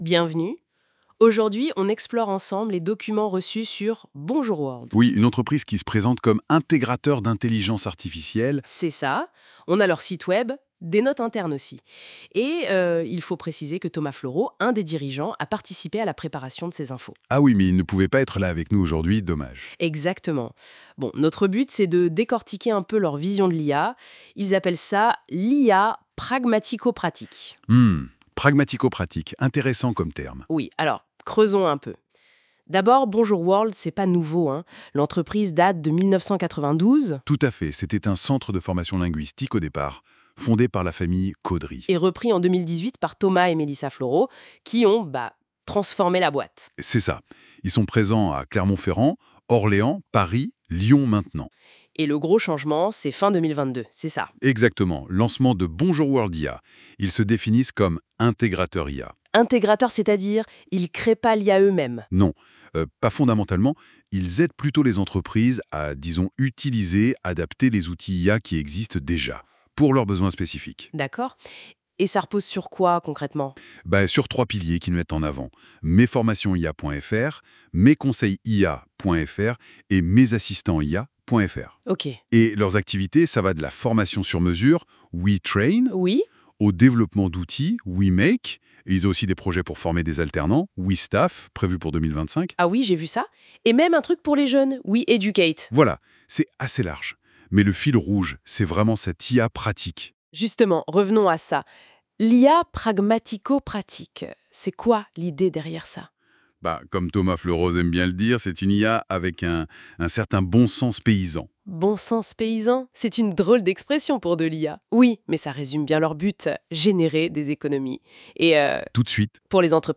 Cliquez ci-dessous pour voir un exemple de podcast en français issu de NotebookLM sur une présentation de BONJOUR WORLD. La fluidité est bluffante tout de même…